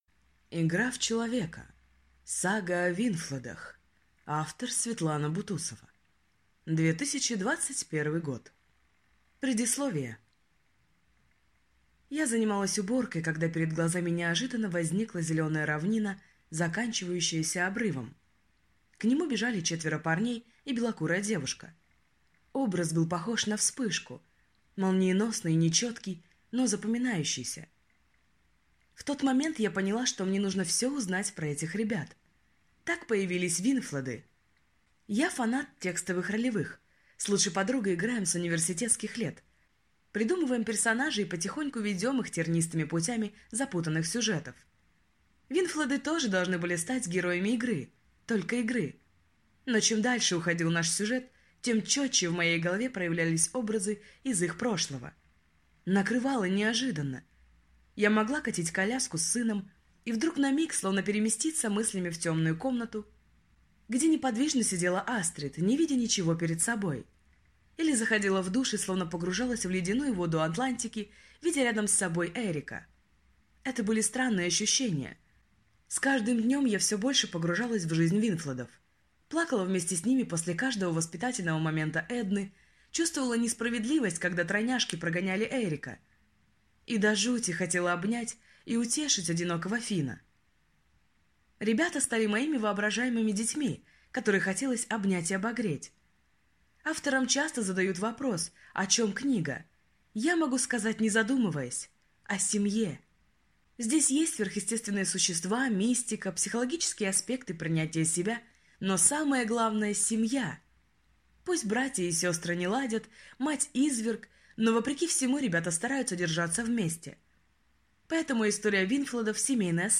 Аудиокнига Игра в человека: Сага о Виннфледах | Библиотека аудиокниг
Прослушать и бесплатно скачать фрагмент аудиокниги